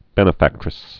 (bĕnə-făktrĭs)